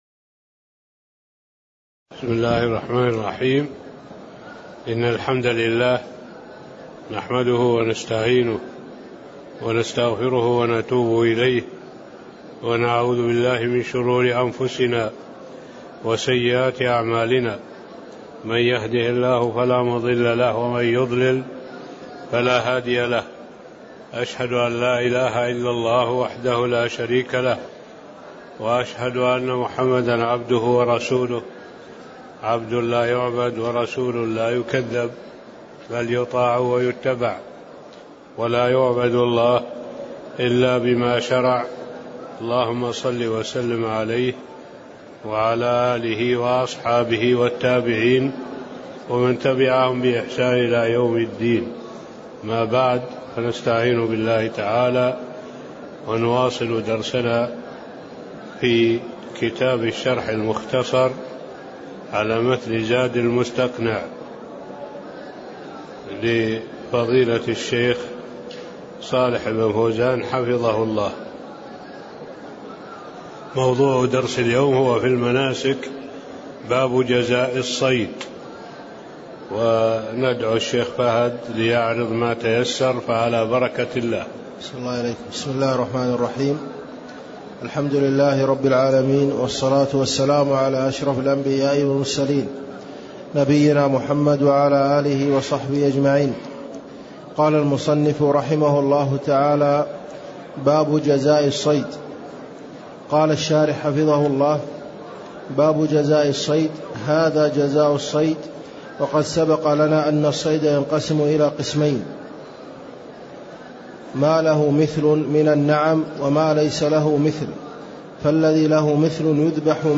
تاريخ النشر ٢٤ شوال ١٤٣٤ هـ المكان: المسجد النبوي الشيخ